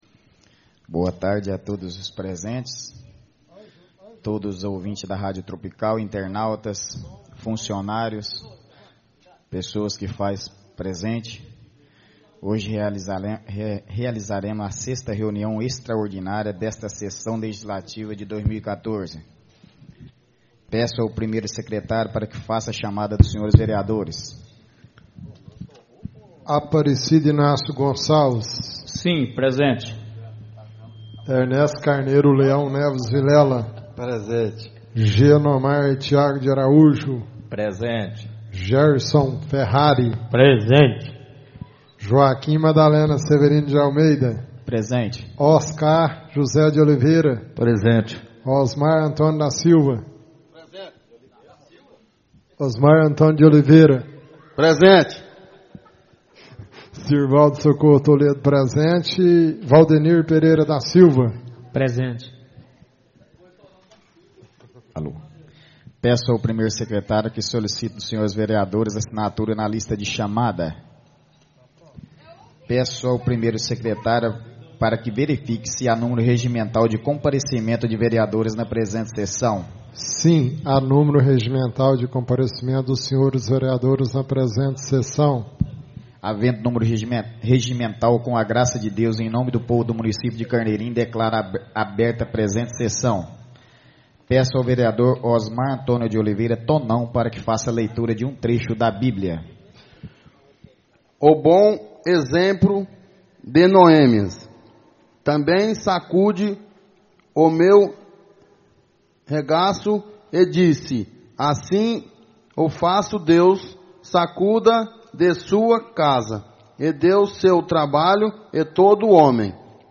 Áudio da 6ª (sexta) sessão extraordinária de 2014, realizada no dia 26 de Maio de 2014, na sala de sessões da Câmara Municipal de Carneirinho, Estado de Minas Gerais.